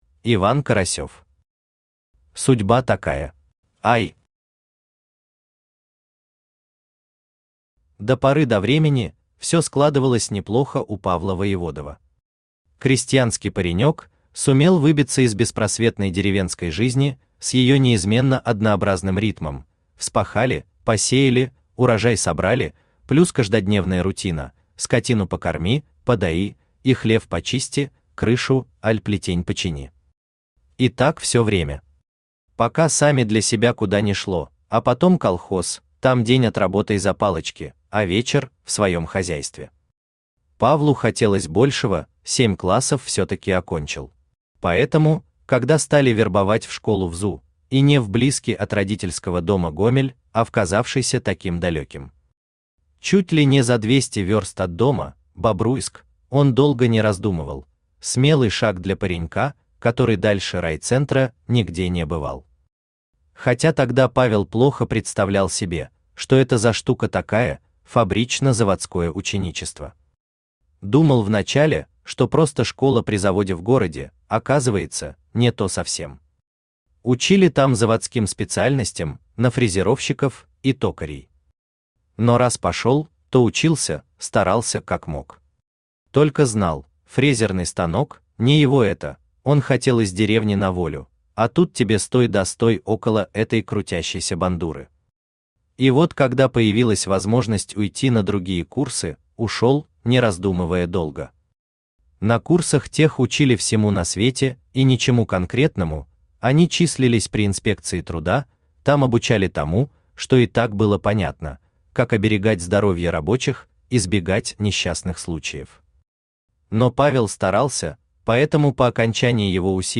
Aудиокнига Судьба такая Автор Иван Карасёв Читает аудиокнигу Авточтец ЛитРес.